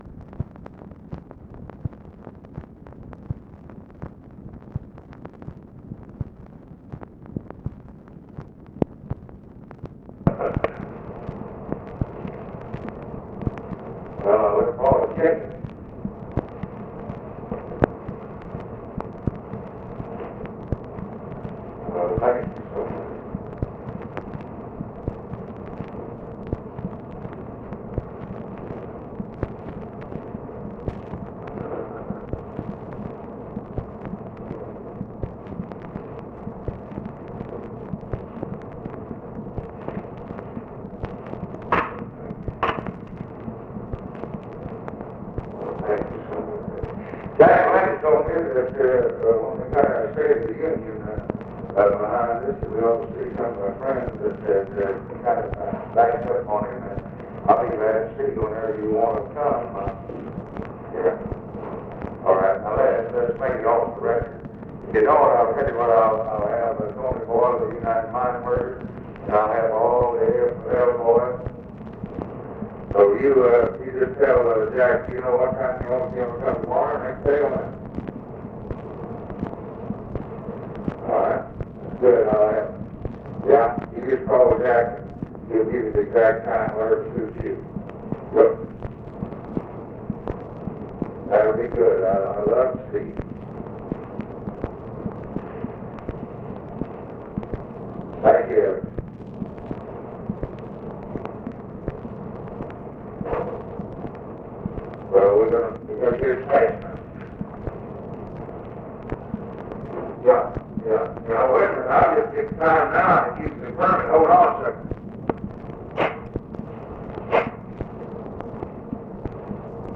OFFICE CONVERSATION, January 5, 1965
Secret White House Tapes